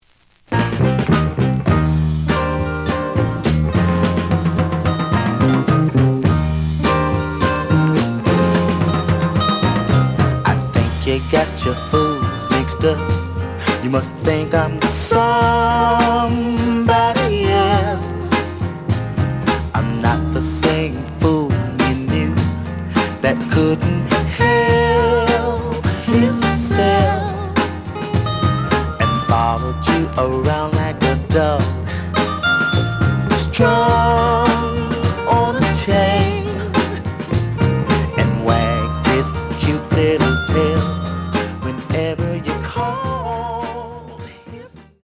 The audio sample is recorded from the actual item.
●Genre: Soul, 60's Soul
Slight noise on both sides.